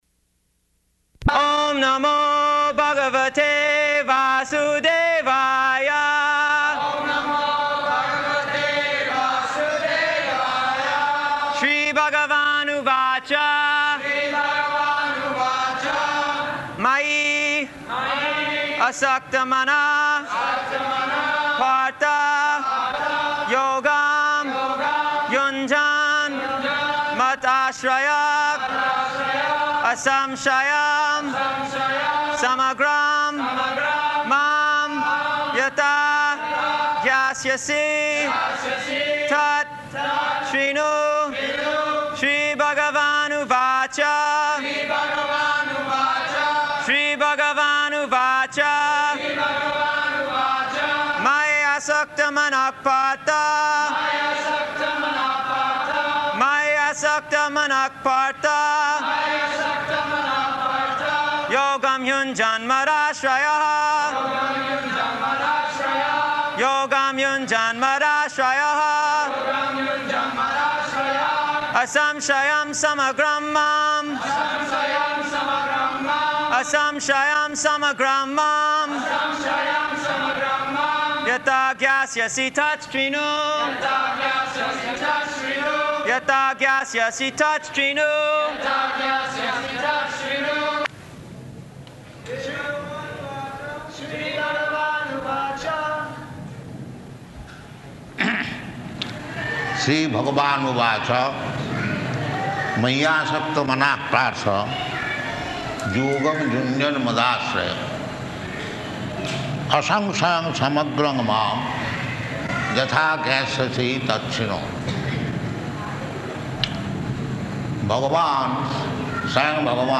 -- Type: Bhagavad-gita Dated: August 26th 1975 Location: Vṛndāvana Audio file